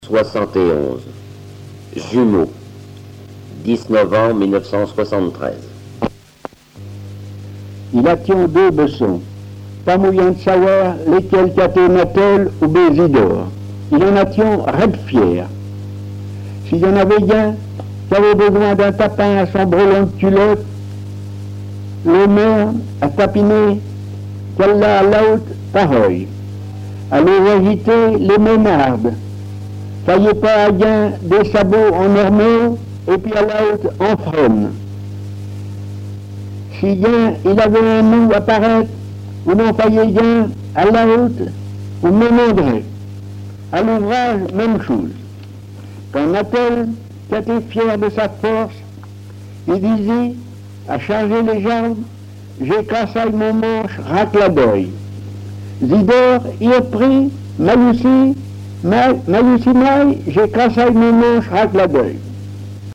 Langue Patois local
Genre récit
Récits en patois